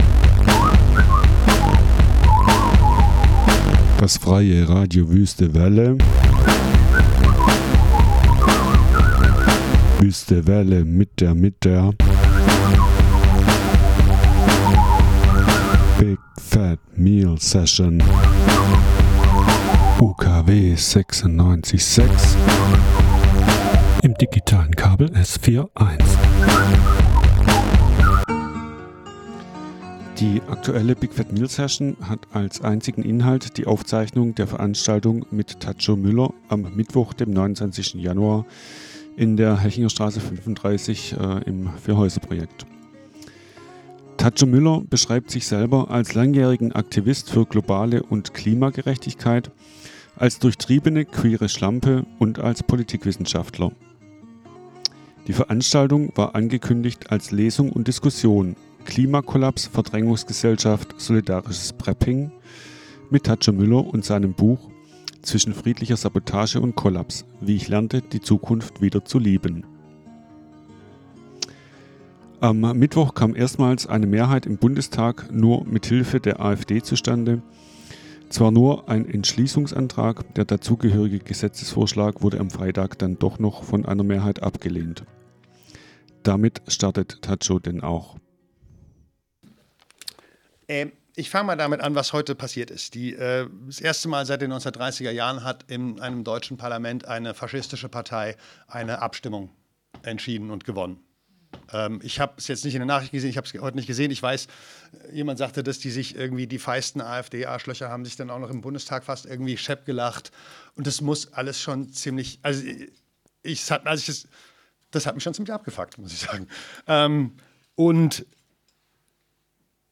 Lesung und Diskussionmit
Die aktuelle BigFatMealSession hat als einzigen Inhalt die Aufzeichnung der Veranstaltung